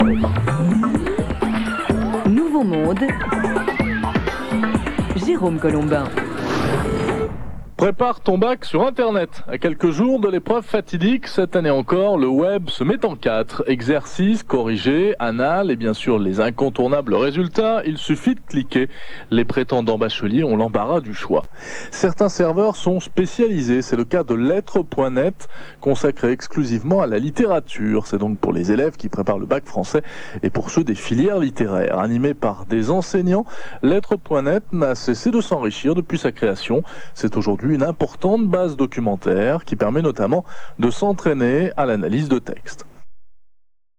Chronique
NB: seul deux extraits de la chronique sont diffusés ici.